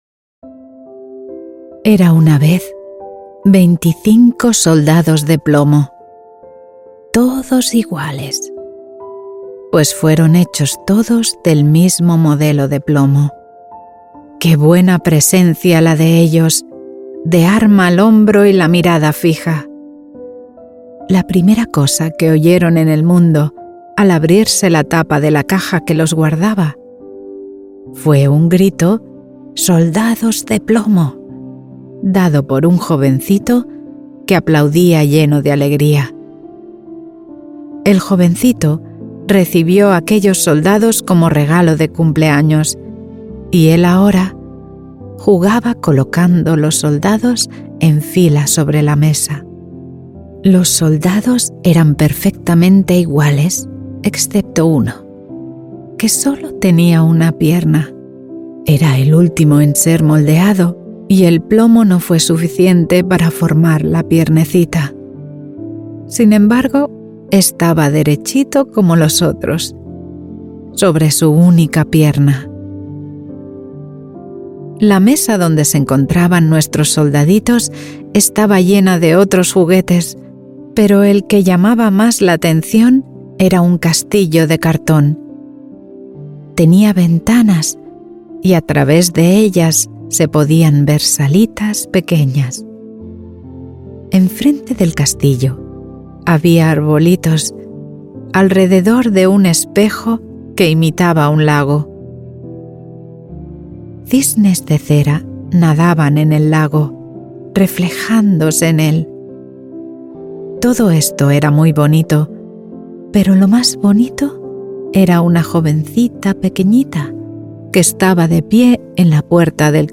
SOFORT-FRIEDEN: Flöten-Meditation mit Wald-Melodie